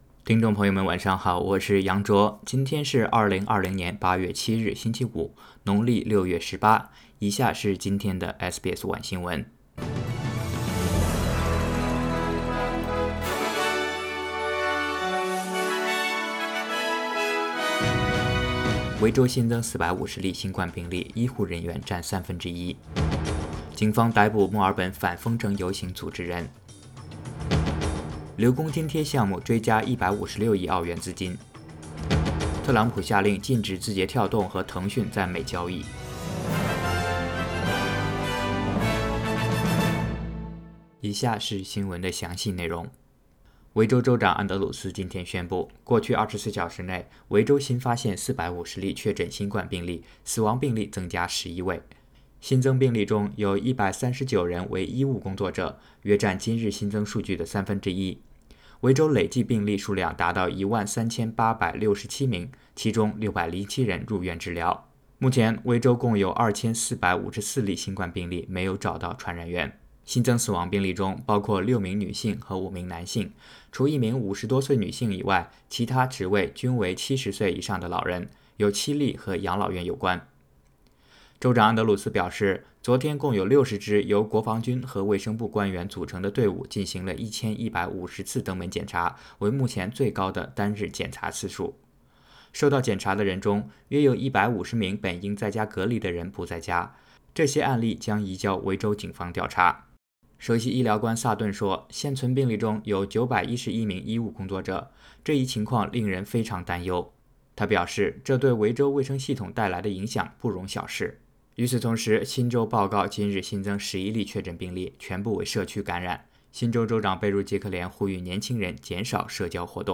SBS晚新闻（8月7日）